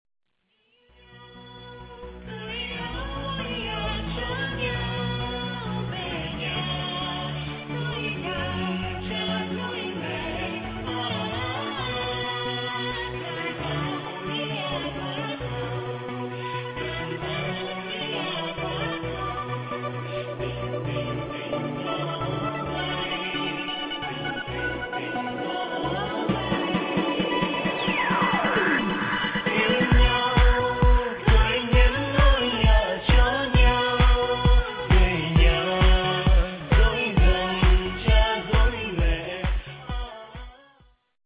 Sáng tác: Dân Ca